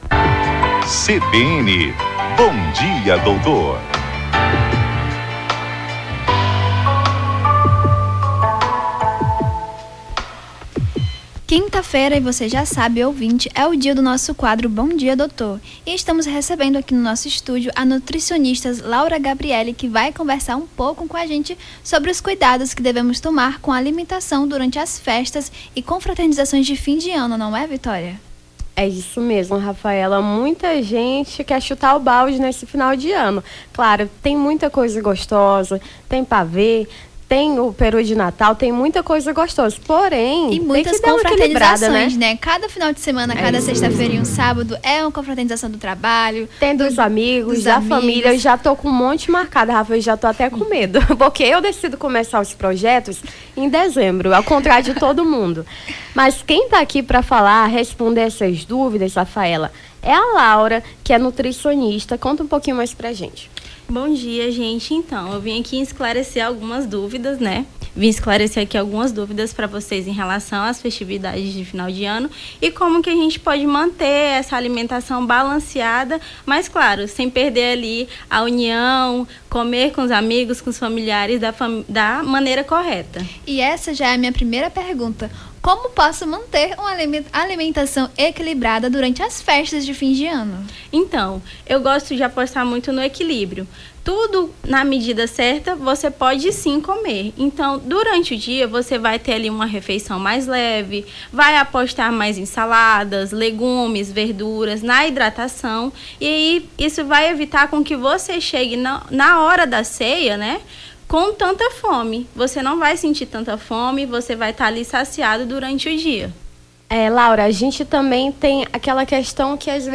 as apresentadoras
conversaram com a nutricionista